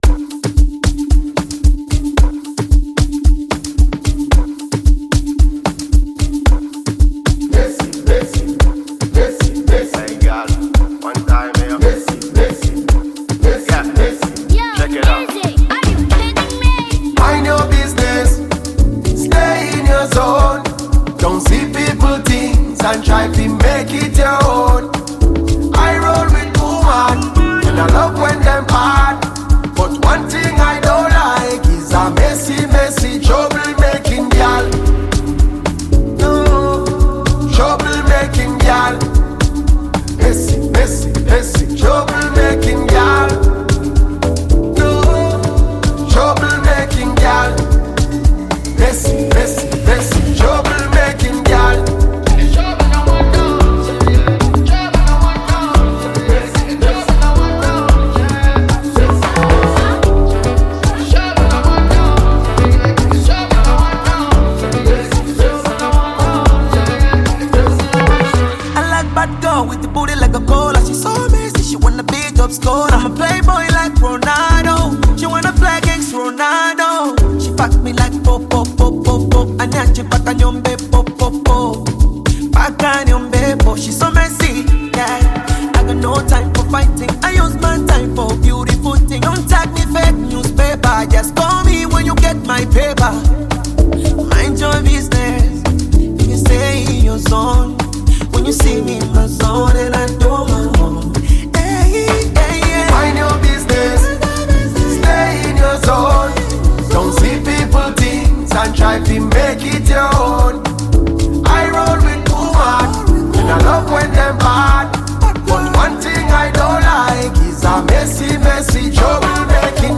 Bongo Flava You may also like